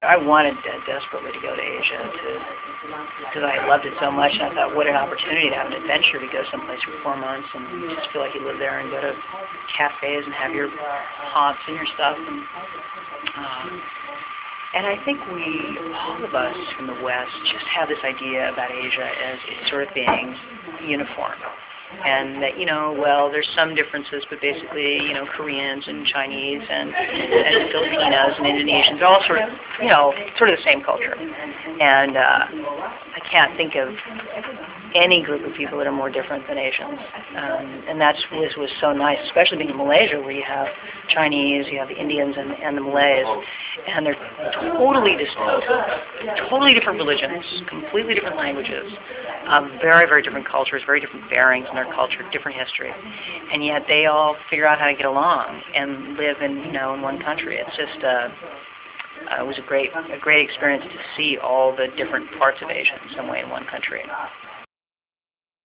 Interview Highlights (Audio)